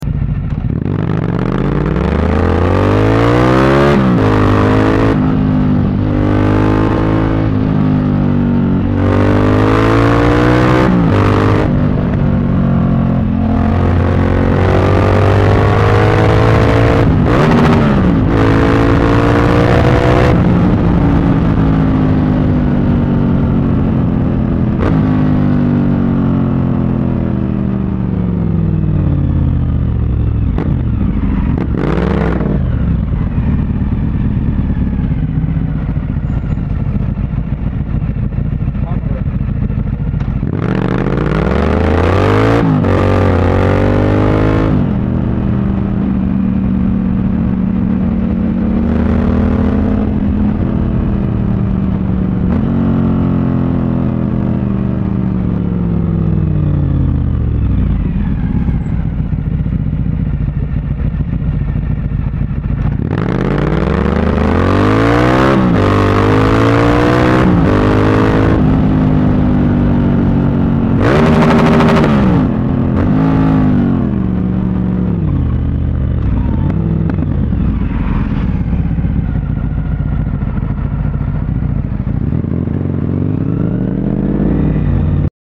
Sonido puro de un motor sound effects free download
Sonido puro de un motor en V, sonido Suzuki V strom 100, 🚀🔥⚙ ¡los motores con más torque!